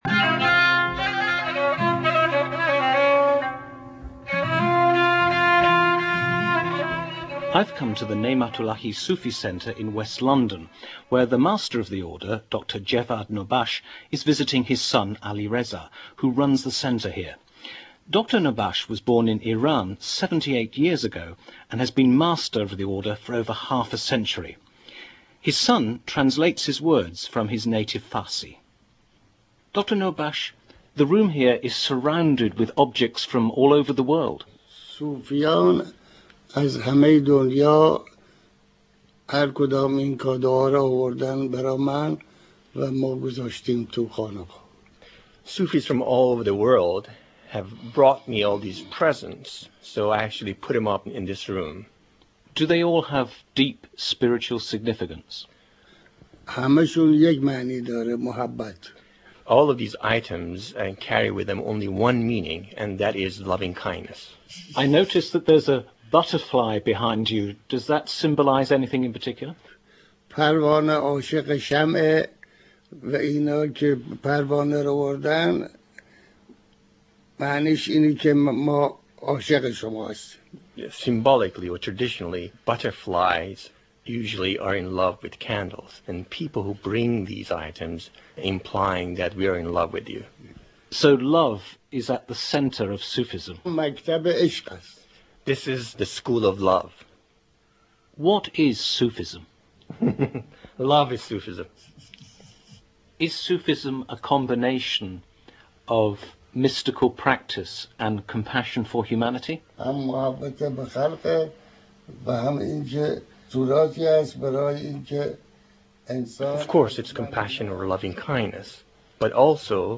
interview-with-the-master-of-the-path.mp3